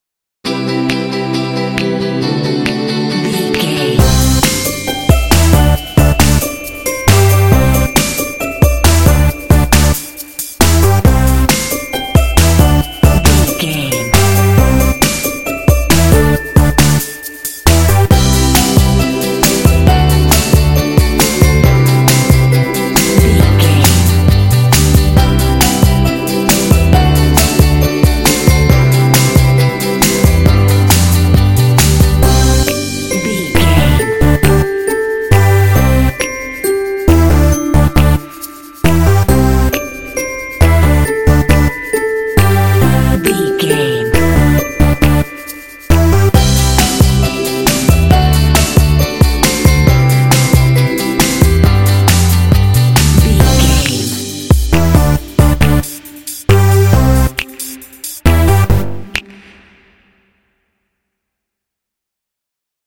Uplifting
Ionian/Major
energetic
driving
playful
cheerful/happy
synthesiser
drums
piano
bass guitar
acoustic guitar
contemporary underscore